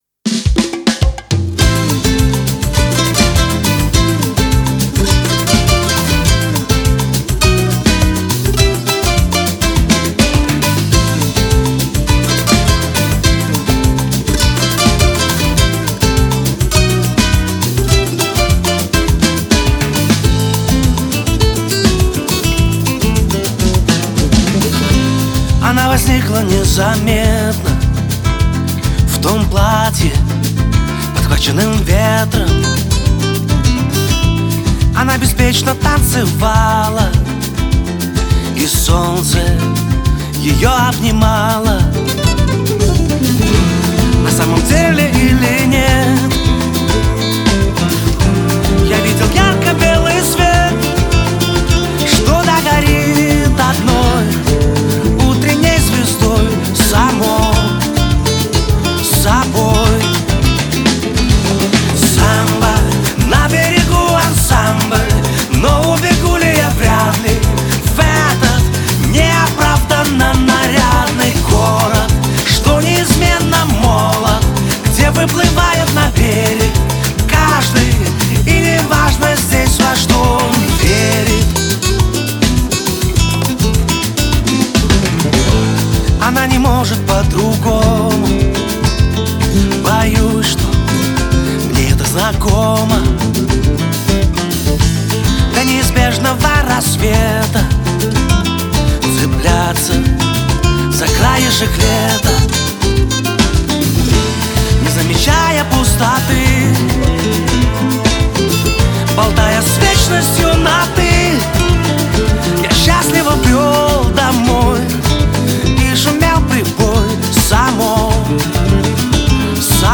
Очень много инструментов